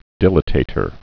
(dĭlə-tātər, dīlə-)